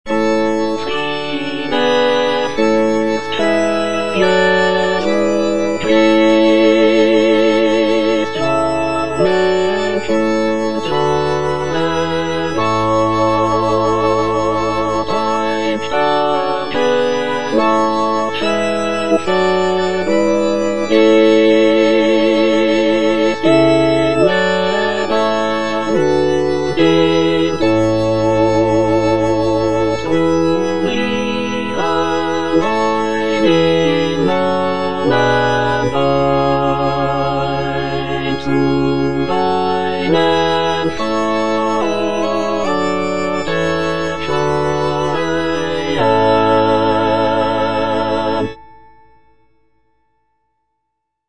(All voices)